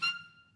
DCClar_stac_F5_v2_rr1_sum.wav